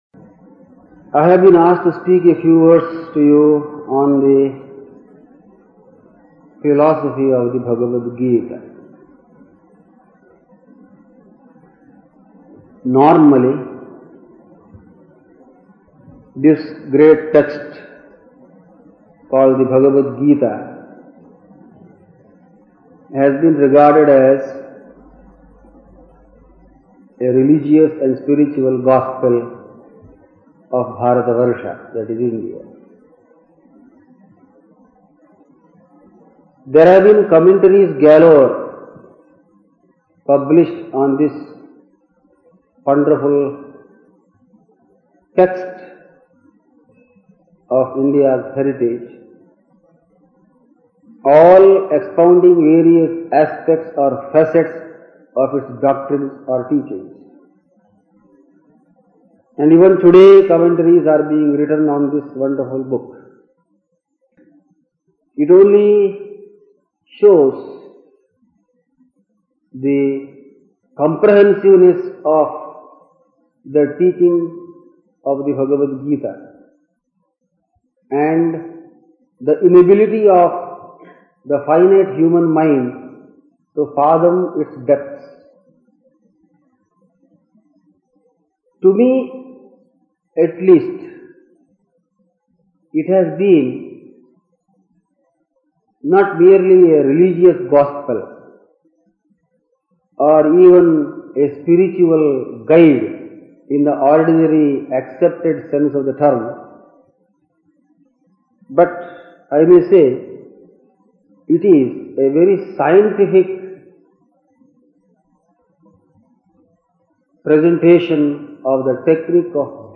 discourse